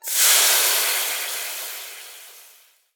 cooking_sizzle_burn_fry_09.wav